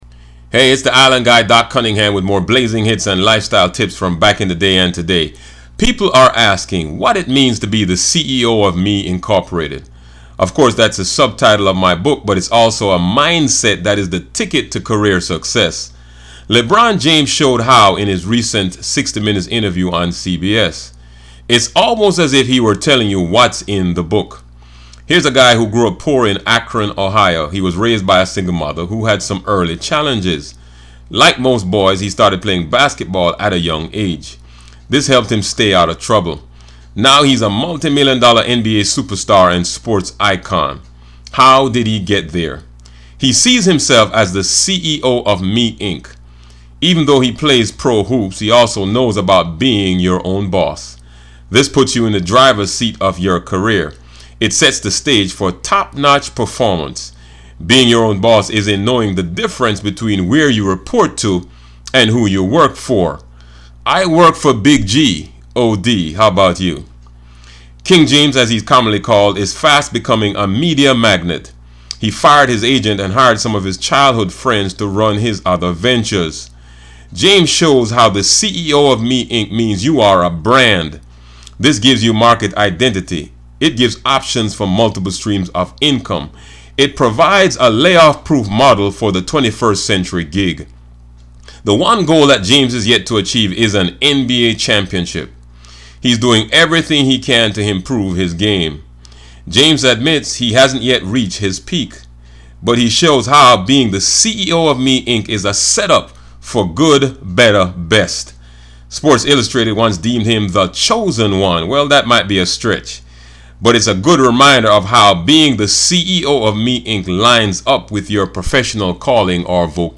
Play ‘R & B Flava’ Podcast track here